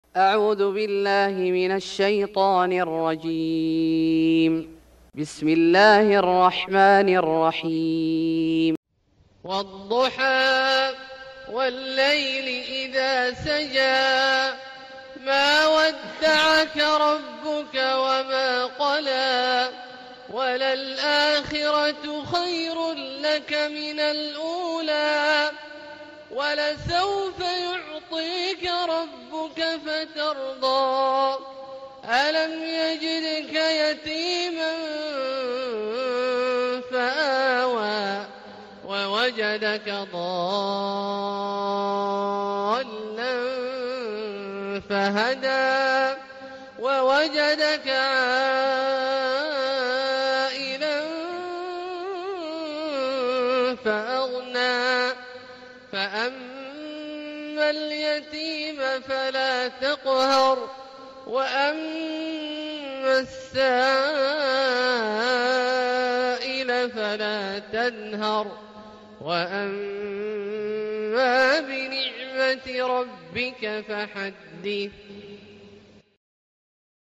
سورة الضحى Surat Ad-Duha > مصحف الشيخ عبدالله الجهني من الحرم المكي > المصحف - تلاوات الحرمين